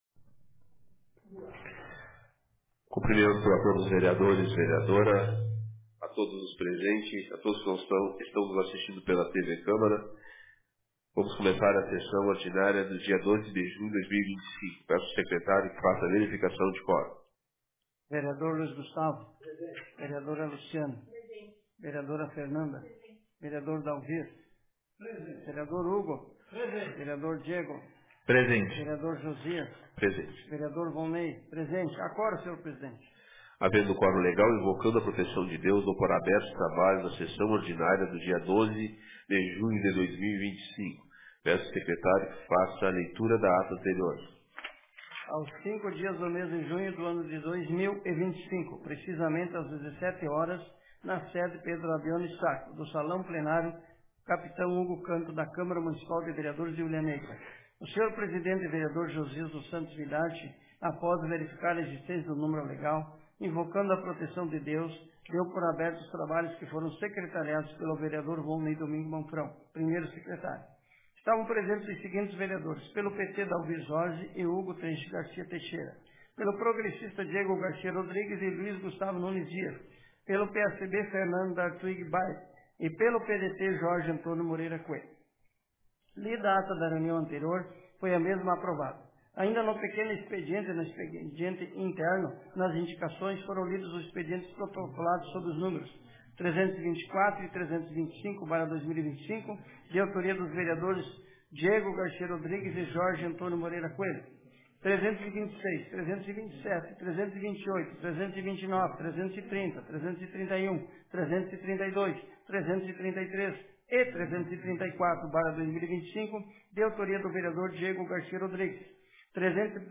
Sessão Ordinária da Câmara de Vereadores de Hulha Negra Data: 12 de junho de 2025